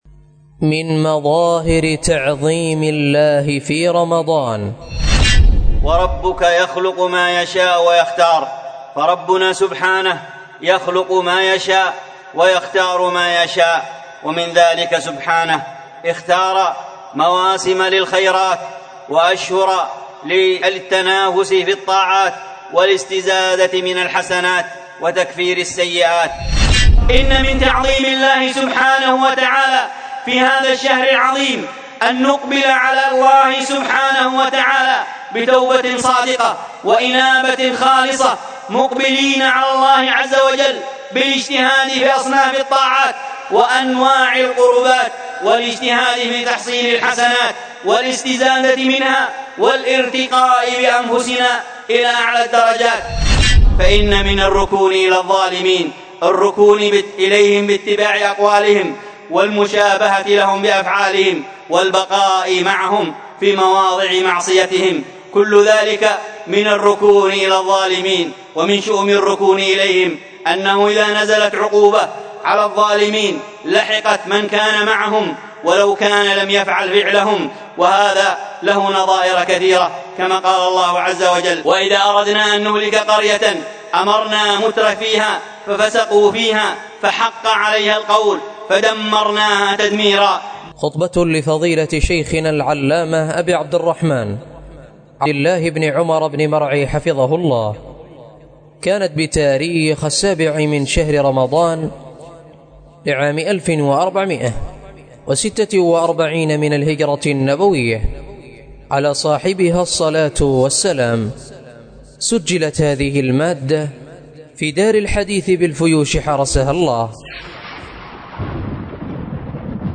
الدرس في القول السديد في مقاصد التوحيد 11، الدرس الحادي عشر:من( الثالثة عشر:مصرف الزكاة ... وإنما الهلاك في ترك ما يقدر عليه العبد من الدعوة إلى هذا الدين ).